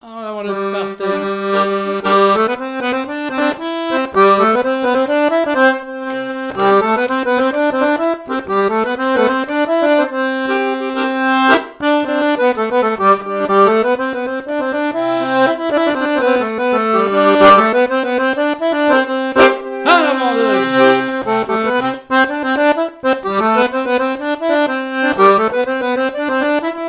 Saint-Georges-de-Montaigu
danse : branle : avant-deux
répertoire de chansons, et d'airs à danser
Pièce musicale inédite